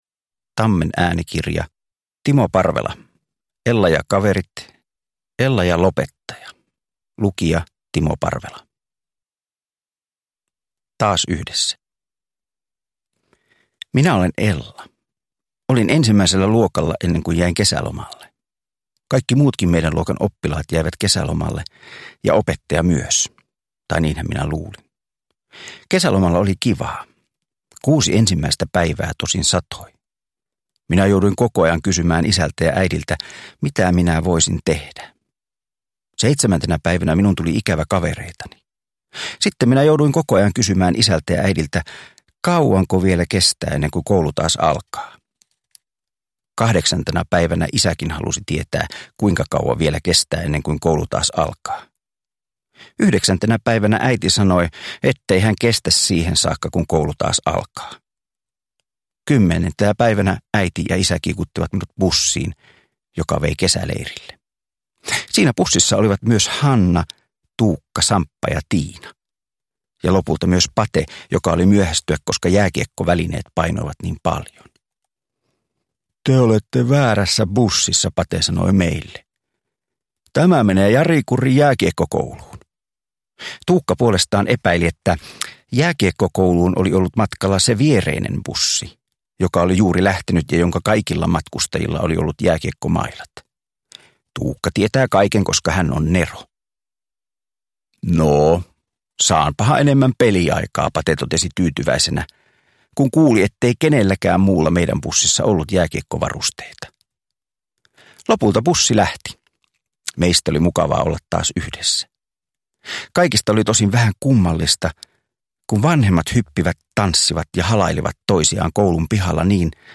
Ella ja lopettaja – Ljudbok
Uppläsare: Timo Parvela